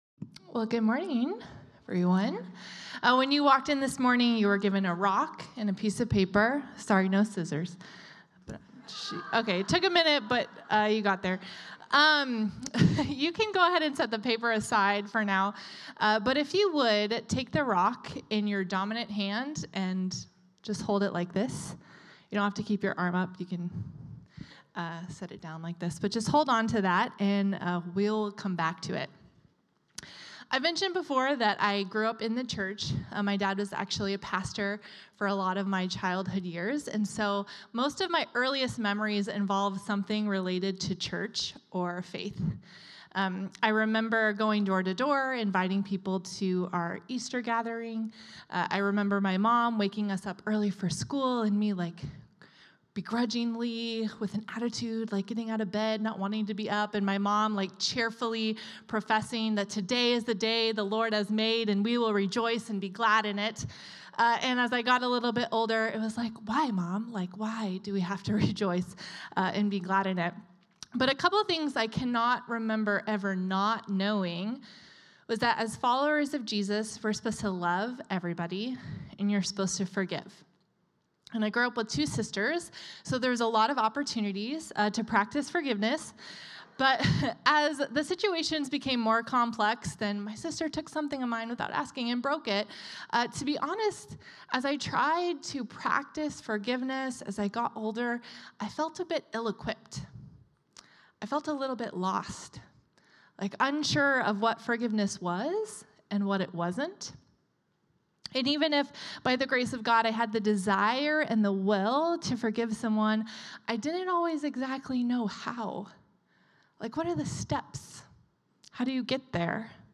The River Church Community Sermons Commissioned in chaos